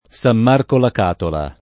Sam m#rko la k#tola] (Puglia), San Marco dei Cavoti [